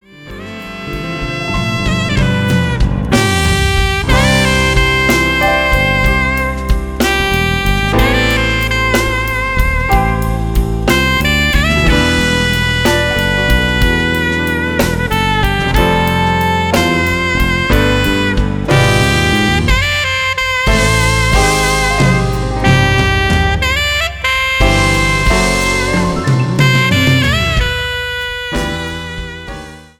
本アルバムは、これまでバンドが日本の名曲をジャズアレンジで演奏してきたライブより厳選し、スタジオ録音した作品。
重厚感のあるサウンドを聴かせる。